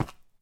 sounds / step / stone3.ogg
stone3.ogg